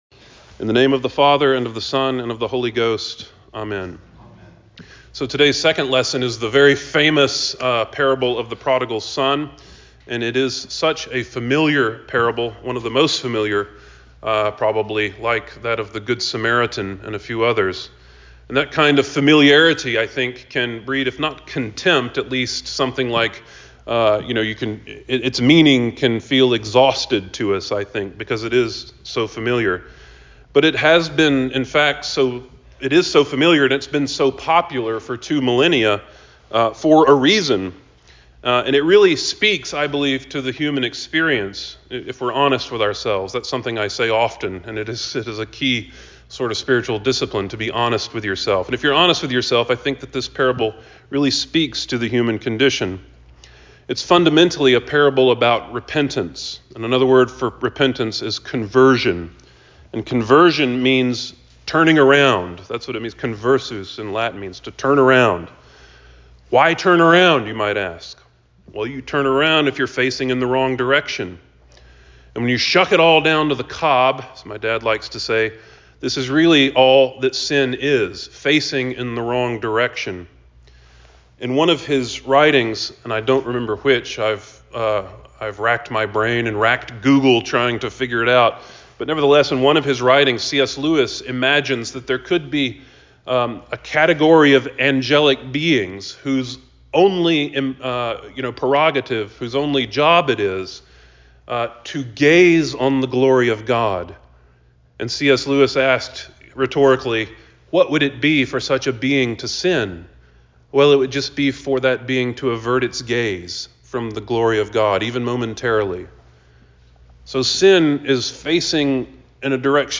Sermons – Page 2 – All Saints' Episcopal Church